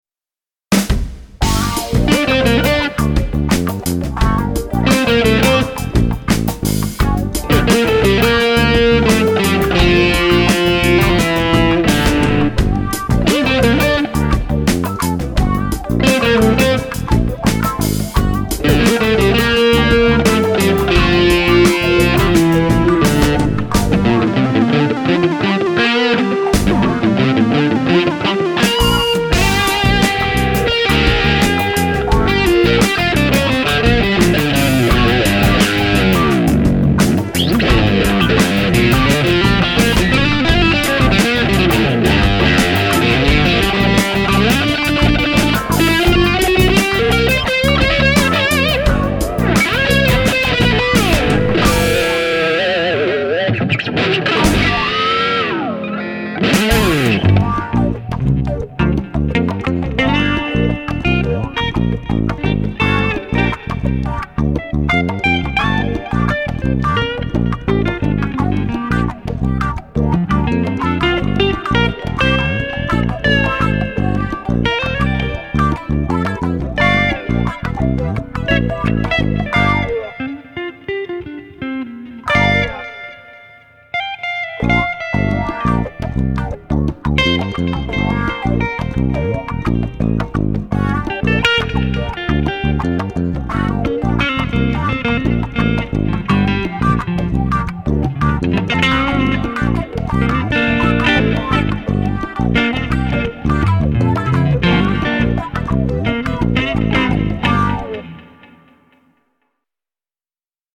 This is a little jam song I was working on this afternoon.
This is 70's cop cool at its best.  The only bit that is somewhat distracting to me is the guitar freakiness around 52 seconds, breaks up an otherwise steady and solid rhythm.
I think the main guitar is a bit too much louder than the rest of the music.
Wah guitar
- Cakewalk Tape Simulator (to make that crunchy lo-fi tape sound)
Congas
Bass Guitar
Drums
Lead Guitar
Synth Lead
boogietothis.mp3